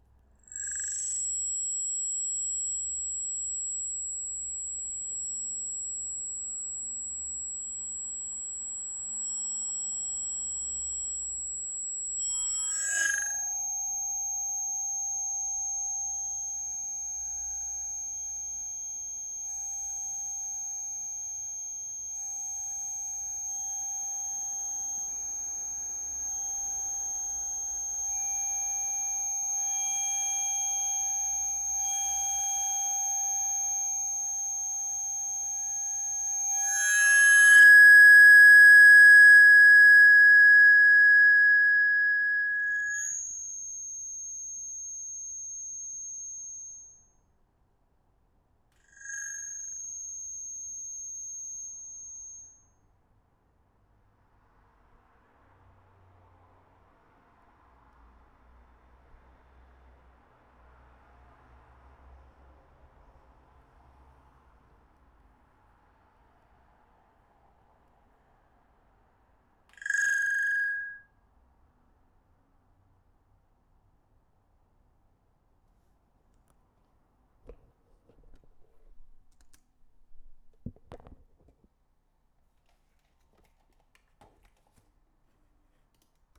feedbackmacbookpro.wav